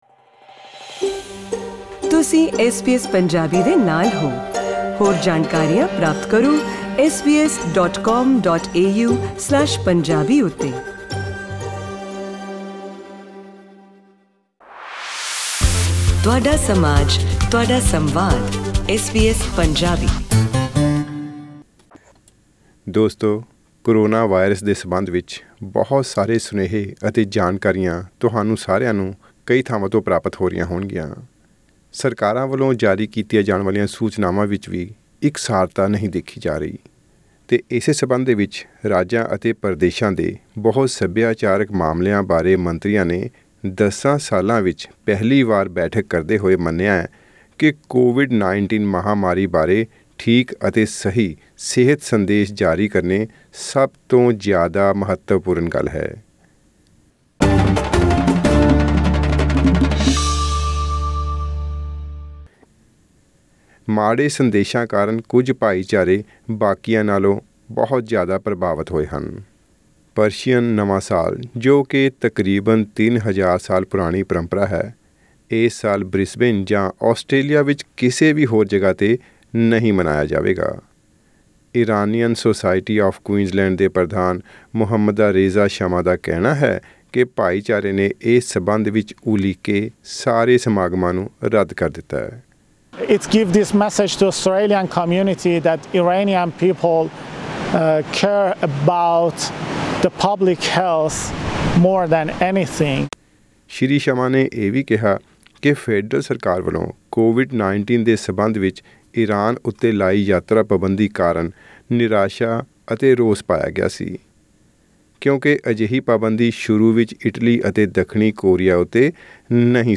ਰਾਜਾਂ ਅਤੇ ਪ੍ਰਦੇਸ਼ਾਂ ਦੇ ਬਹੁਸਭਿਆਚਾਰਕ ਮਾਮਲਿਆਂ ਬਾਰੇ ਮੰਤਰੀਆਂ ਨੇ 10 ਸਾਲਾਂ ਵਿੱਚ ਪਹਿਲੀ ਵਾਰ ਬੈਠਕ ਕਰਦੇ ਹੋਏ ਮੰਨਿਆ ਹੈ ਕਿ ਕੋਵਿਡ-19 ਮਹਾਂਮਾਰੀ ਬਾਰੇ ਠੀਕ ਅਤੇ ਸਹੀ ਸਿਹਤ ਸੰਦੇਸ਼ ਜਾਰੀ ਕਰਨਾ ਸਭ ਤੋਂ ਜਿਆਦਾ ਮਹੱਤਵਪੂਰਨ ਗੱਲ ਹੈ। ਮਾੜੇ ਸੰਦੇਸ਼ਾਂ ਕਾਰਨ ਕੁੱਝ ਭਾਈਚਾਰੇ ਬਾਕੀਆਂ ਨਾਲੋਂ ਕਿਤੇ ਜਿਆਦਾ ਪ੍ਰਭਾਵਤ ਹੋਏ ਹਨ। ਜਿਆਦਾ ਜਾਣਕਾਰੀ ਲਈ ਸੁਣੋ ਇਹ ਆਡੀਓ ਰਿਪੋਰਟ.........